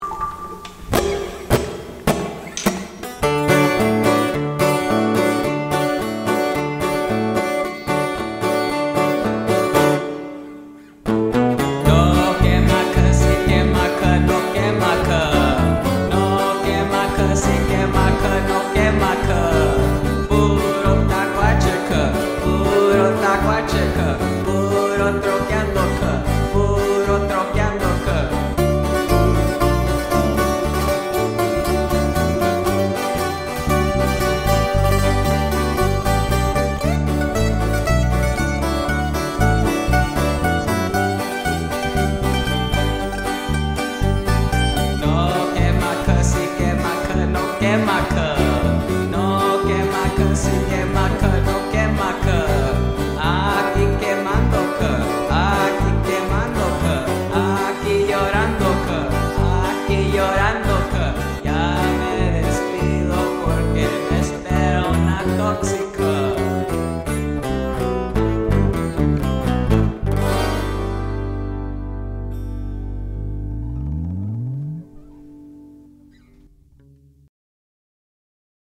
No quema cuh cumbia - MP3 Download